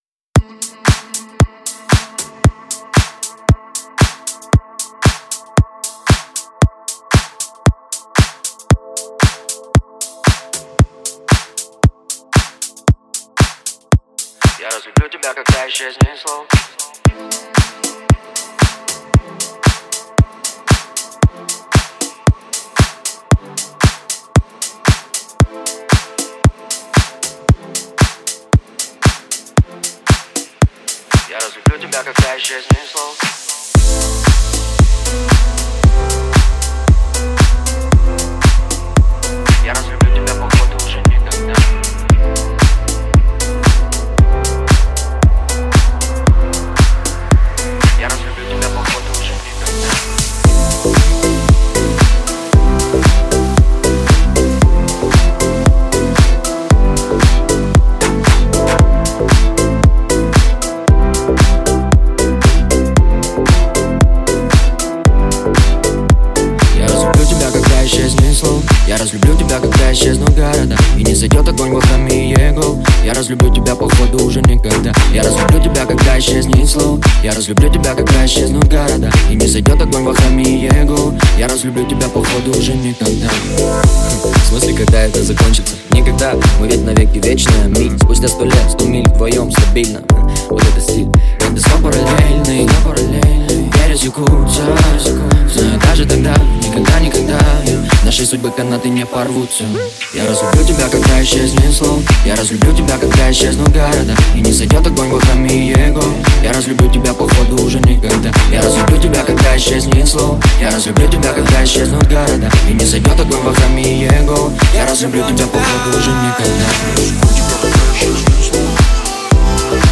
Электроника
Жанр: Жанры / Электроника